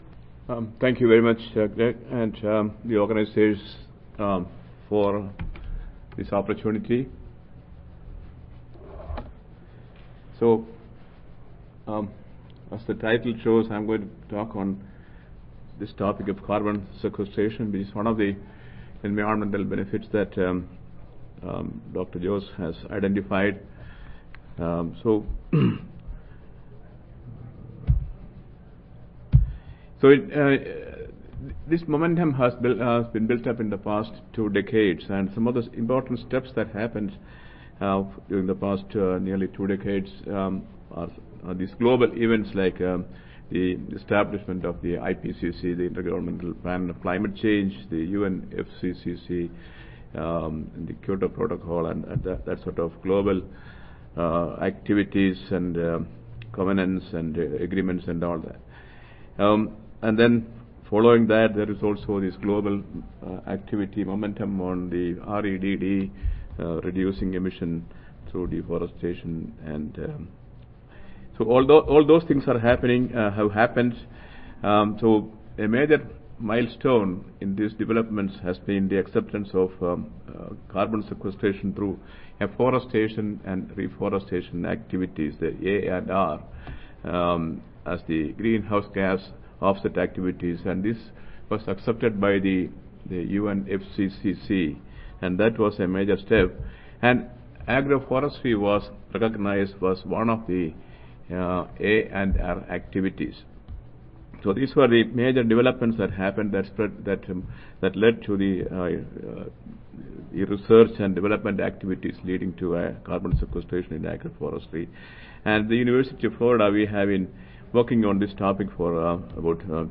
See more from this Division: SSSA Cross-Divisional Symposium See more from this Session: CrossDiv--Symposium--Agroforestry for Sustainable Resource Management and Food Security